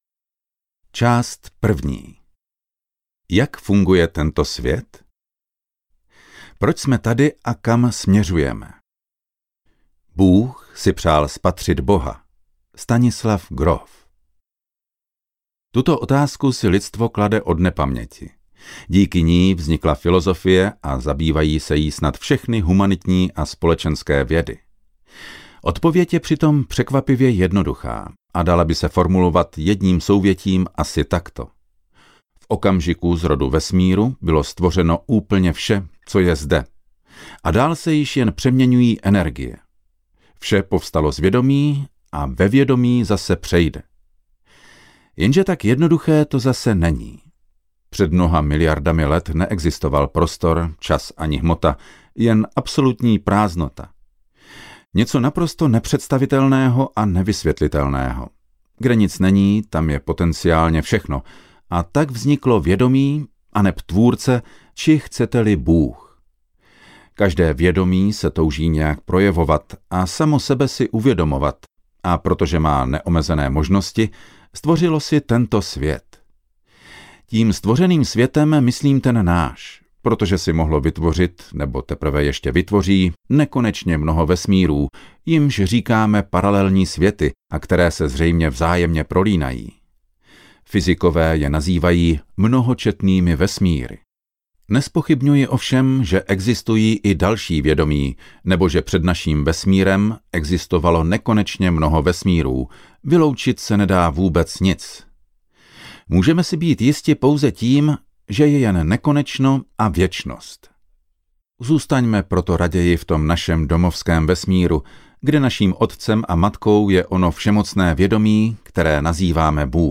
Audiokniha Indigová realita - Simon Loyd | ProgresGuru